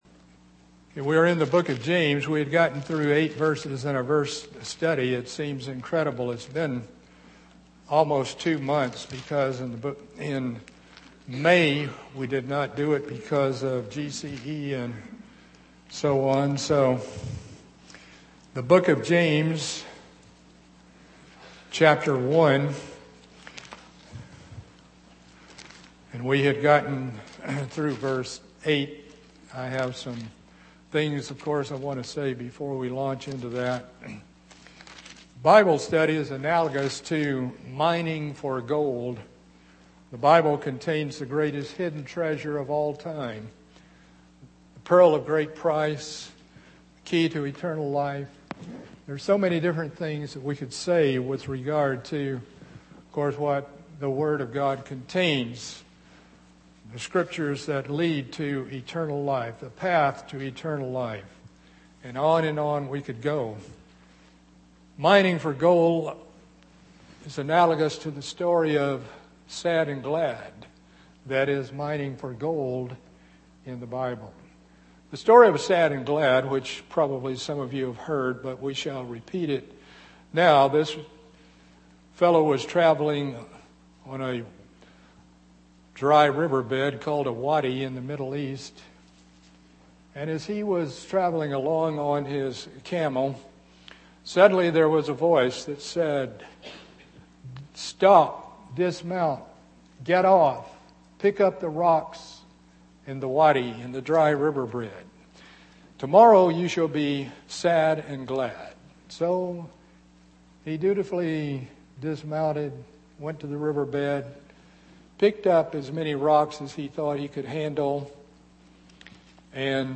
A continuing Bible study of the book of James.